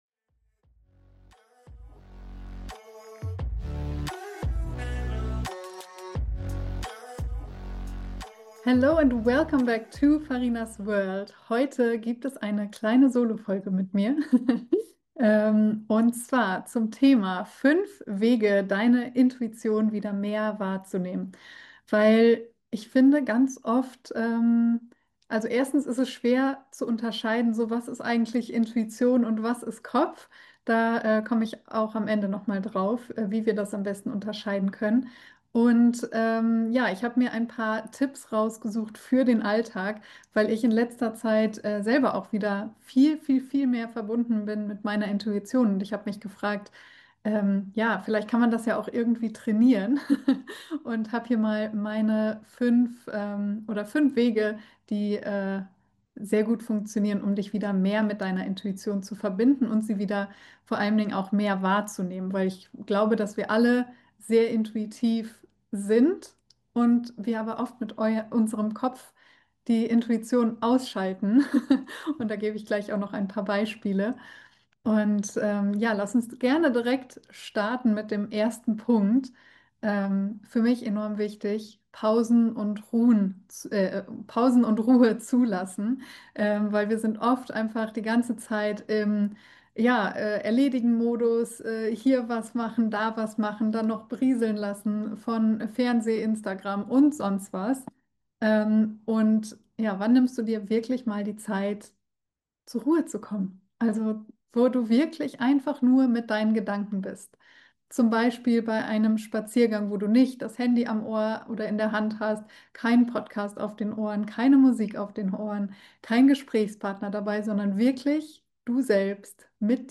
Heute gibt’s mal wieder eine Solofolge von mir, die ganz intuitiv entstanden ist Während um mich herum alle im Urlaub sind, habe ich die Ruhe genutzt, um mit dir über ein Thema zu sprechen, das mich in letzter Zeit intensiv begleitet: meine Int...